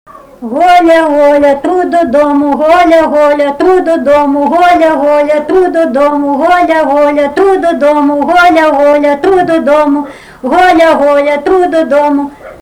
smulkieji žanrai